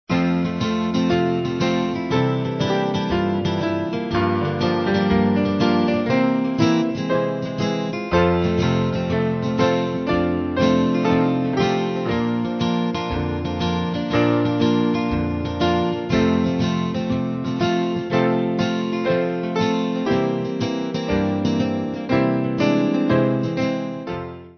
Easy Listening
Mainly Piano